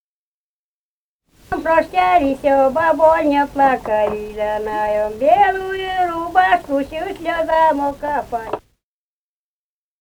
частушки